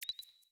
song-ping-variation-3.wav